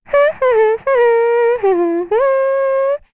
Rehartna-Vox_Hum1_kr.wav